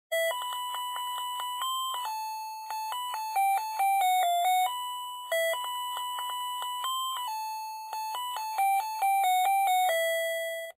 ZVONČEK 16 MELÓDIÍ
• elektronický
• zvuk: 16 striedajúcich sa druhov melódií